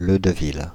Fr-Paris--Leudeville.ogg